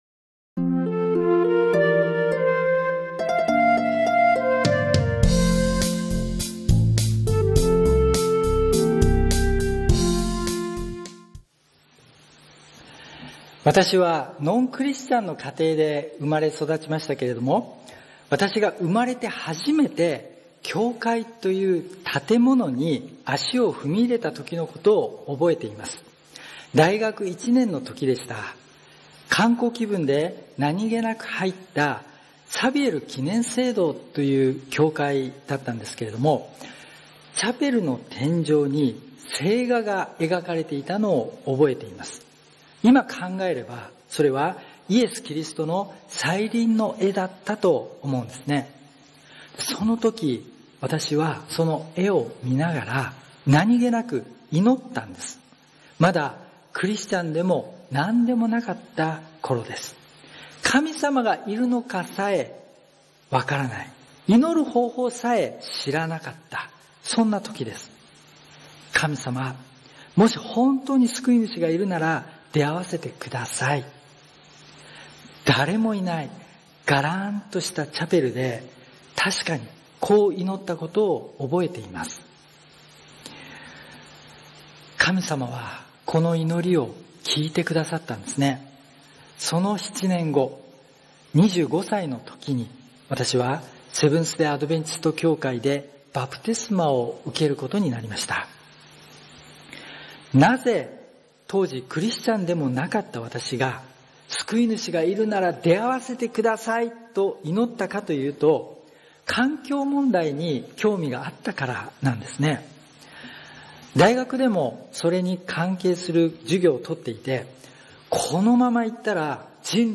礼拝メッセージ172 またおいでになる